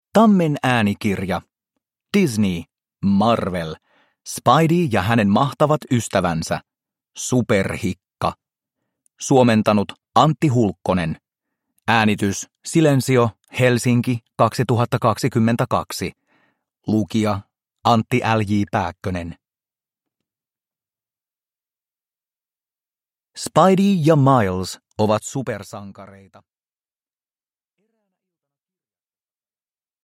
Spidey ja hänen mahtavat ystävänsä. Superhikka – Ljudbok – Laddas ner